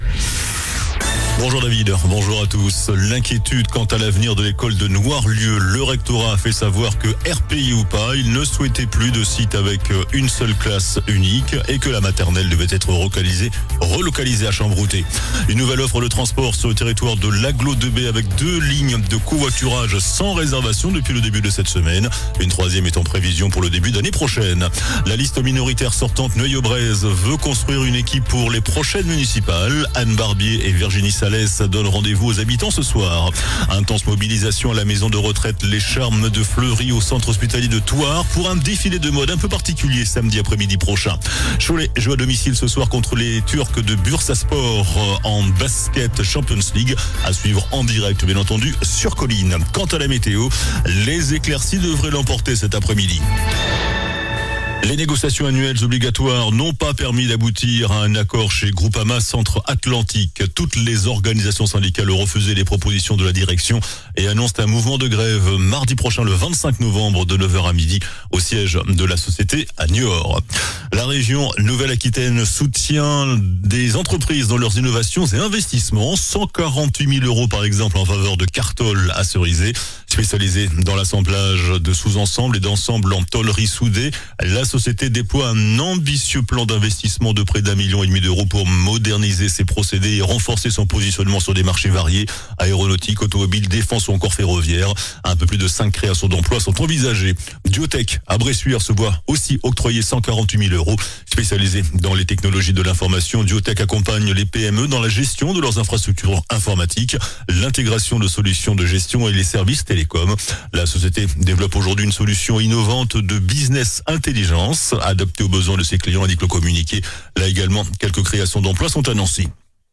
JOURNAL DU MERCREDI 19 NOVEMBRE ( MIDI )